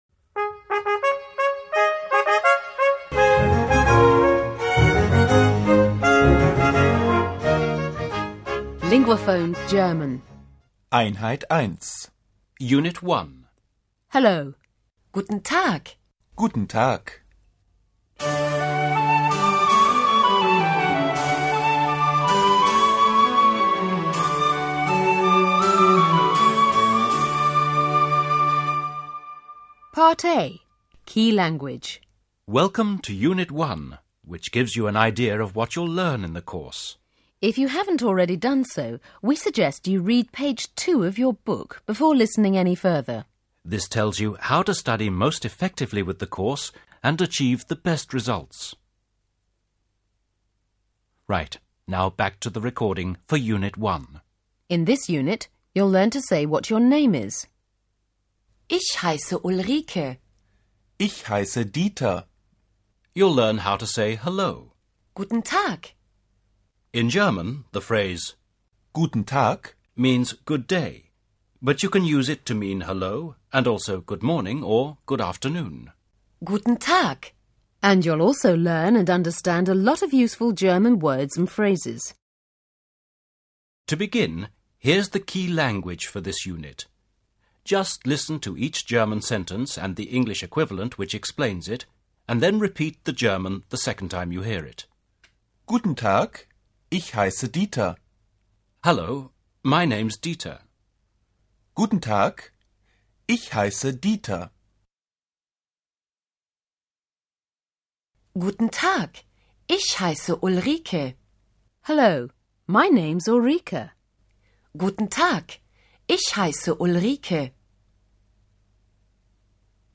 German PDQ Free Audio Lesson